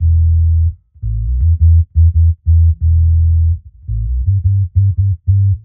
Index of /musicradar/dub-designer-samples/85bpm/Bass
DD_JBass_85_E.wav